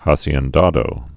(häsē-ĕn-dädō, ä-)